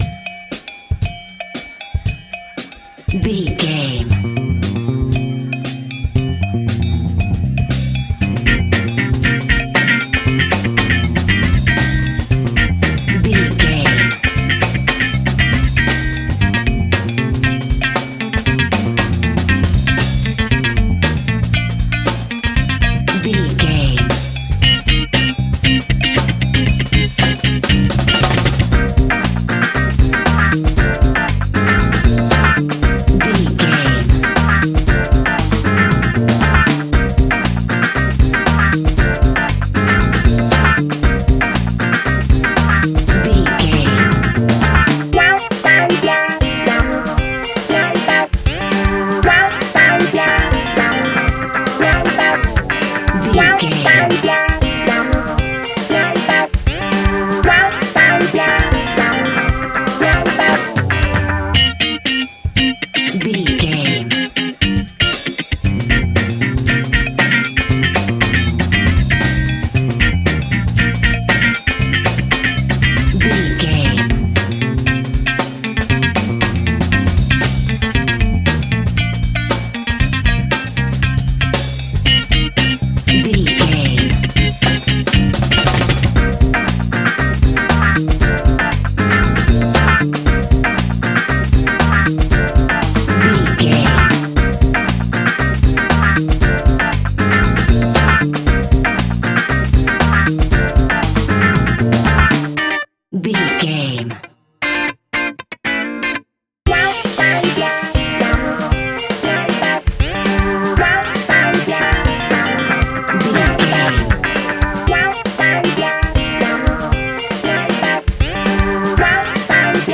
Aeolian/Minor
uplifting
bass guitar
electric guitar
organ
percussion
saxophone